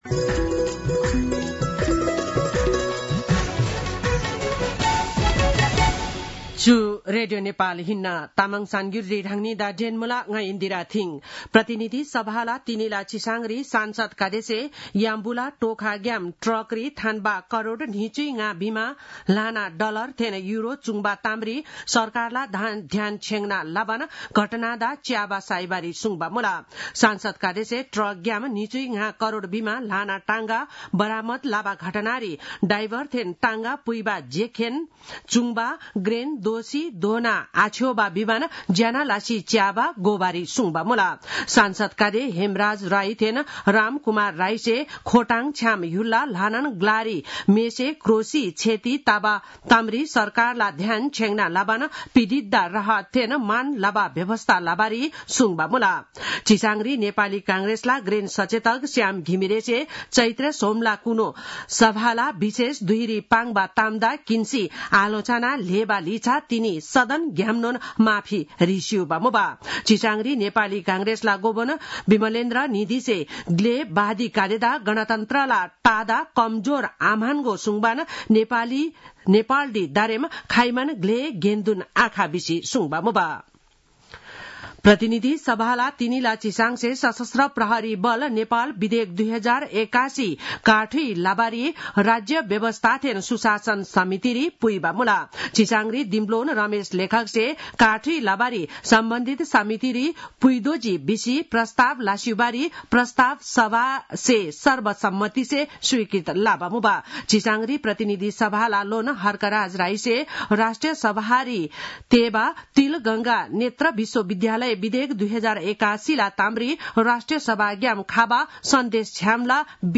तामाङ भाषाको समाचार : ६ चैत , २०८१